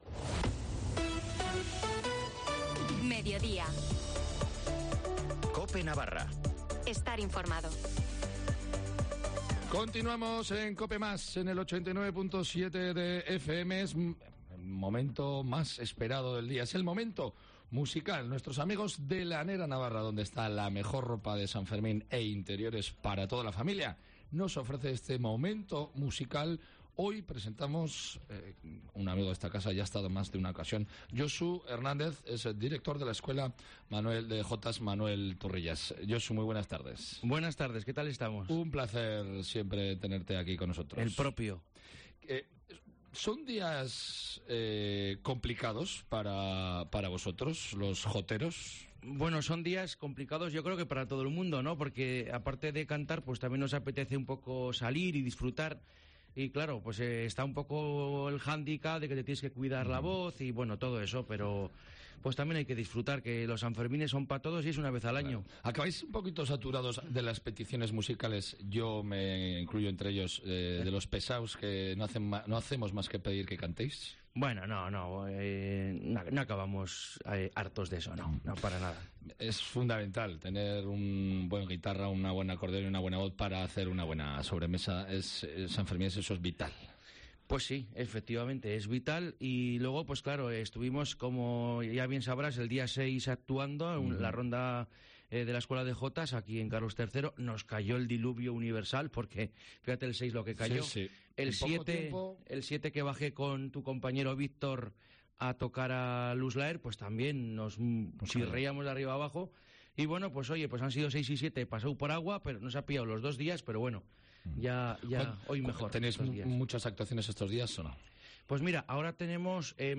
SANFERMINES